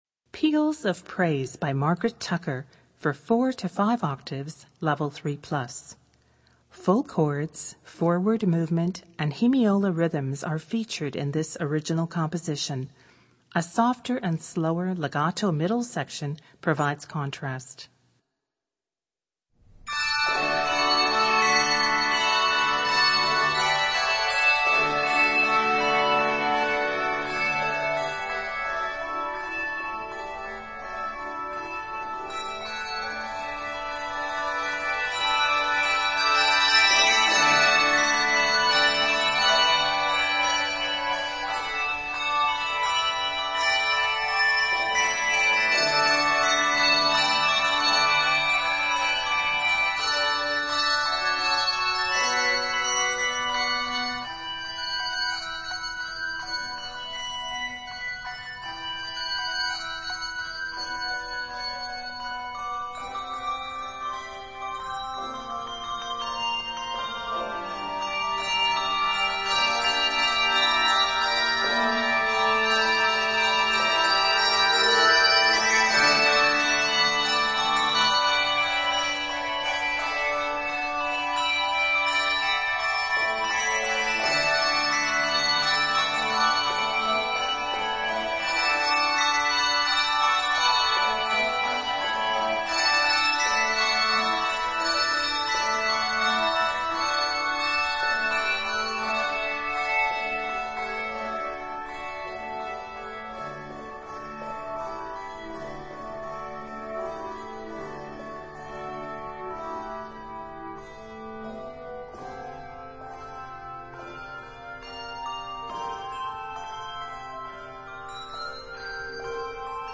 Voicing: Handbells 4-5 Octave